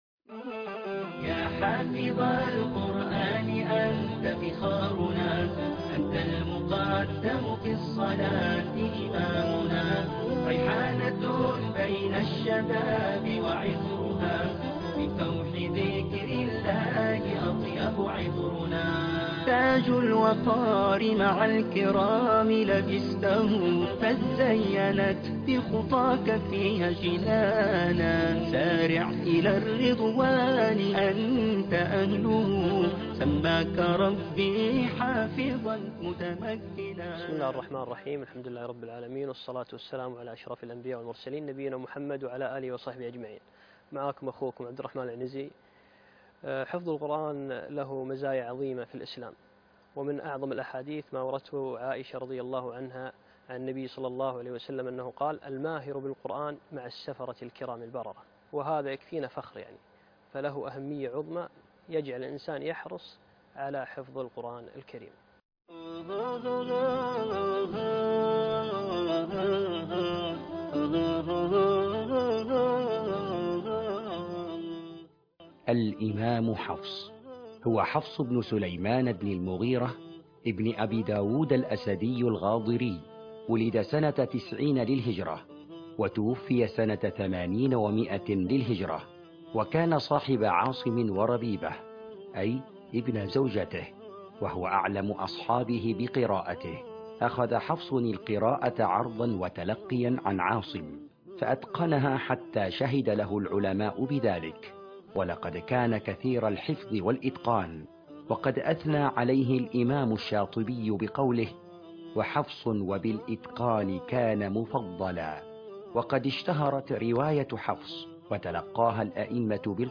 القاريء